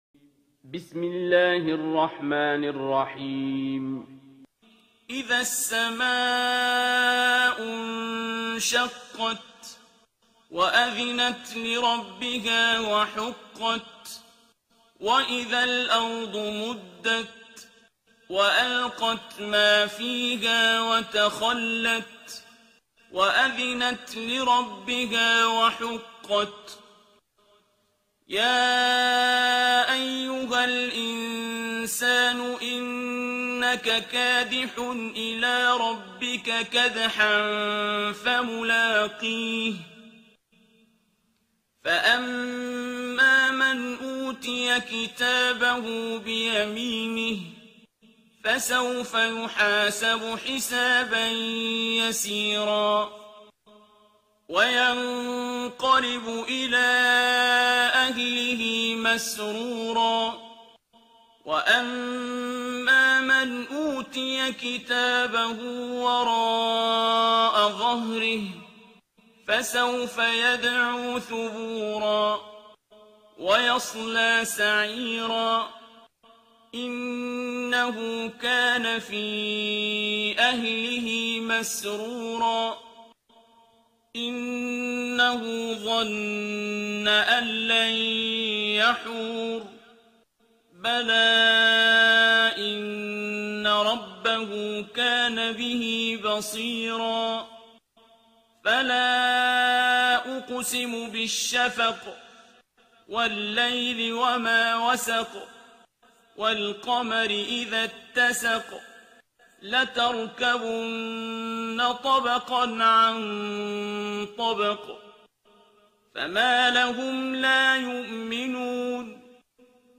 ترتیل سوره انشقاق با صدای عبدالباسط عبدالصمد
084-Abdul-Basit-Surah-Al-Inshiqaq.mp3